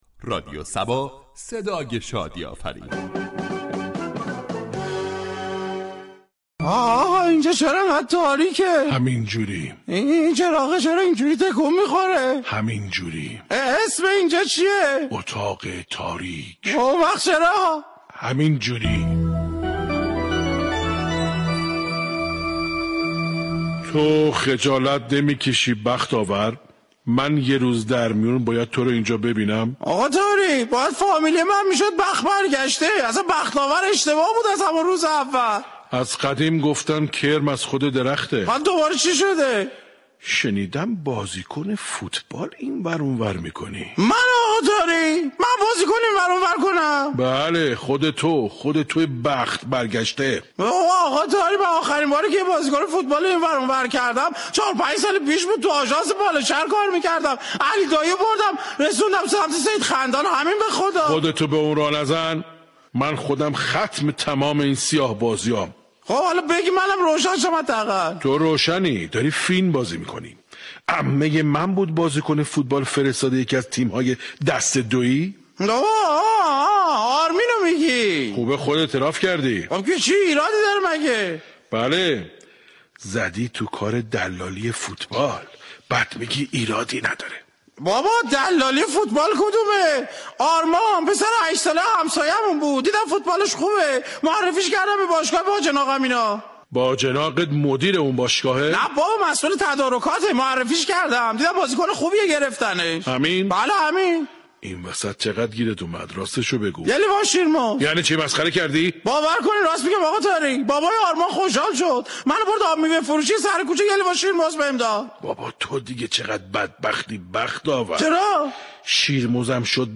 شهر فرنگ در بخش نمایشی با بیان طنز به موضوع "مافیای فوتبال "پرداخته است ،در ادامه شنونده این بخش باشید.